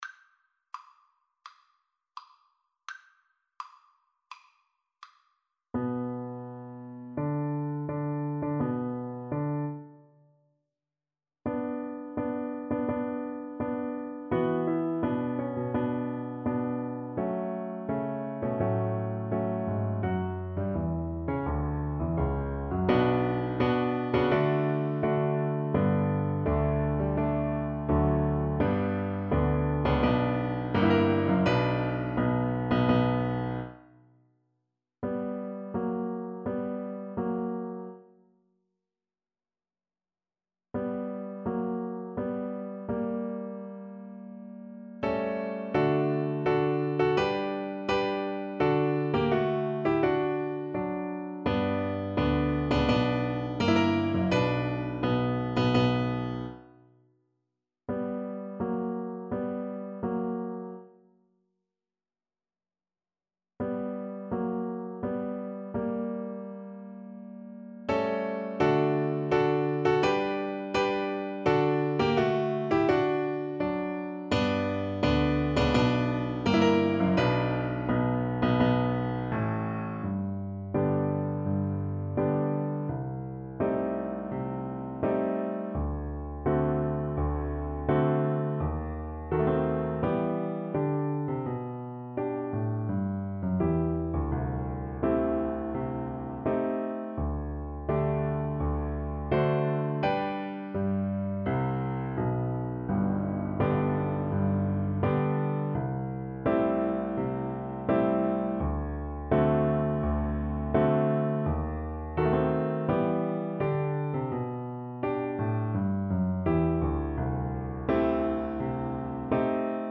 Play (or use space bar on your keyboard) Pause Music Playalong - Piano Accompaniment Playalong Band Accompaniment not yet available reset tempo print settings full screen
Tempo di Marcia =84
D major (Sounding Pitch) (View more D major Music for Violin )
Classical (View more Classical Violin Music)